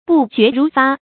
不絕如發 注音： ㄅㄨˋ ㄐㄩㄝˊ ㄖㄨˊ ㄈㄚ 讀音讀法： 意思解釋： 絕：斷。形容局勢危急 出處典故： 東漢 班固《漢書 燕刺王劉旦傳》：「先日諸呂陰謀大逆，劉氏 不絕如發 。」